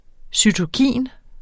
Udtale [ sytoˈkiˀn ]